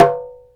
DJEMBE 3A.WAV